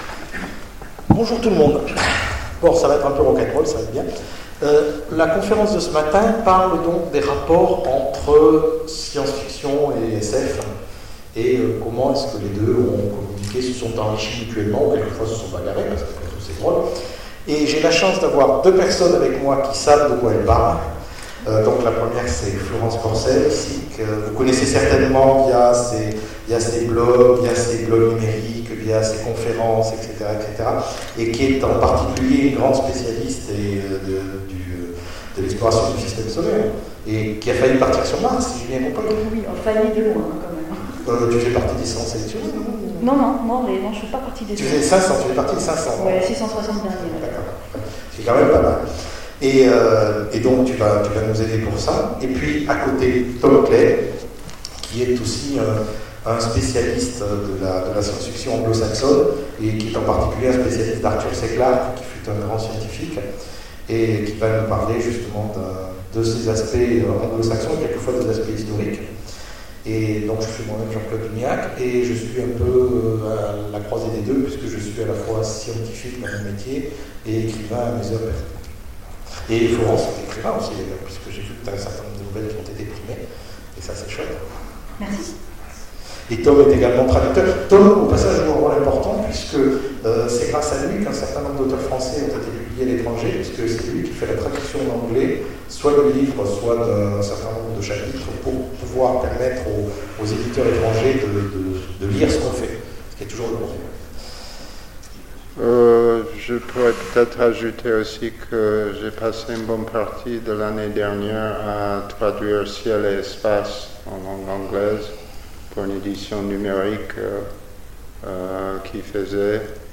Intergalactiques 2016 : Table ronde L'influence de la SF britannique sur la science moderne
Mots-clés sciences Conférence Partager cet article